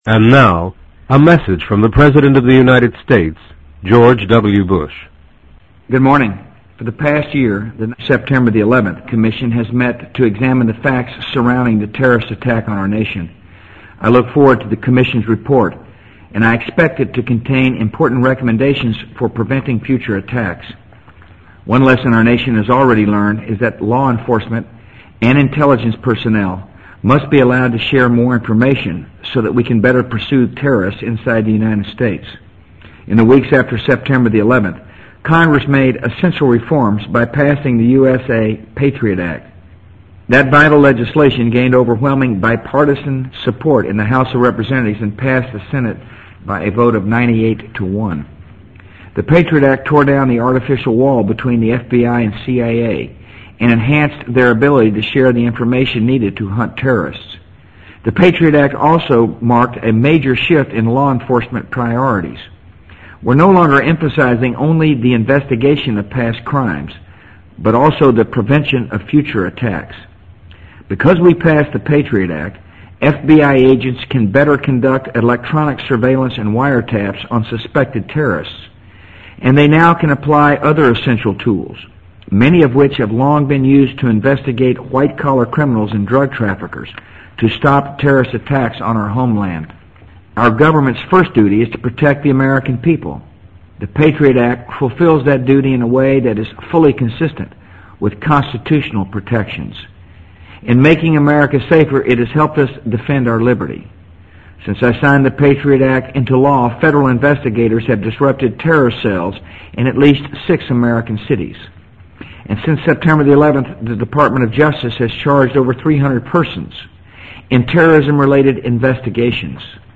【美国总统George W. Bush电台演讲】2004-04-17 听力文件下载—在线英语听力室